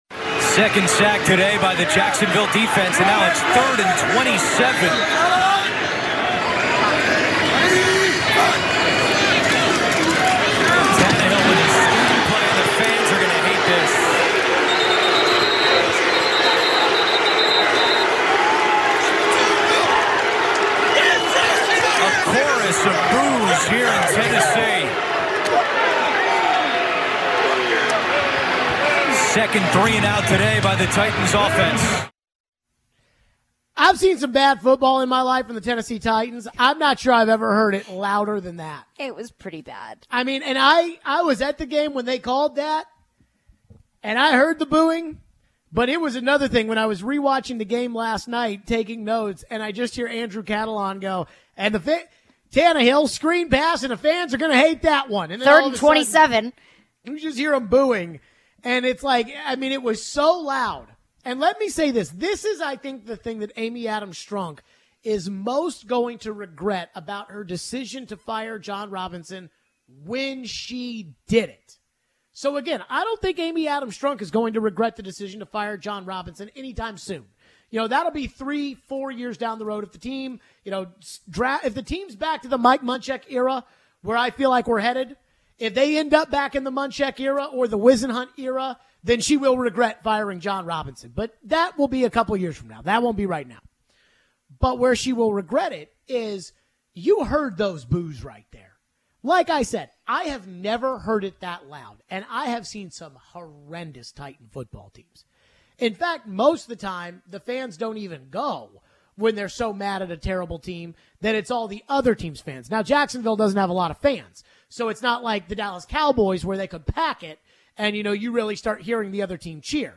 Vrabel talked about how to move forward as a team. Did Miss Amy pull the plug on the season and what was the benefit of making the move in season? We take your phones.